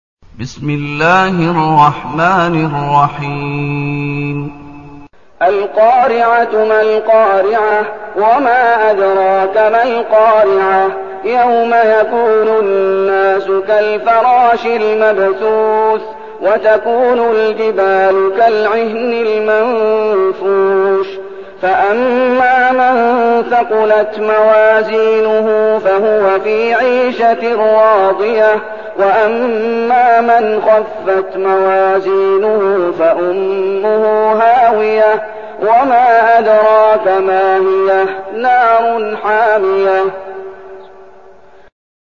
المكان: المسجد النبوي الشيخ: فضيلة الشيخ محمد أيوب فضيلة الشيخ محمد أيوب القارعة